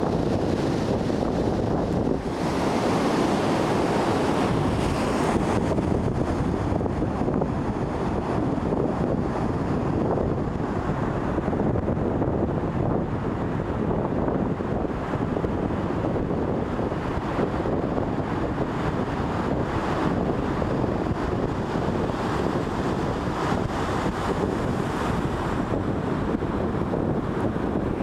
C’est la pointe de l’île, entre le continent et la mer, avec beaucoup de vent et des courants forts.
Para oir el ambiente sonoro:
Pour entendre l’ambiance :
vagues.mp3